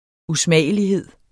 Udtale [ uˈsmæˀjəliˌheðˀ ]